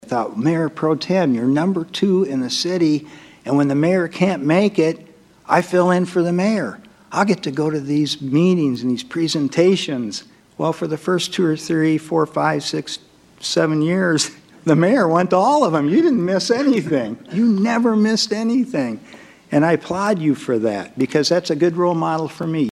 THIS PAST MONDAY WAS THE FINAL CITY COUNCIL MEETING OF 2025, AND ALSO THE FINAL COUNCIL MEETING FOR THREE OF ITS FIVE MEMBERS.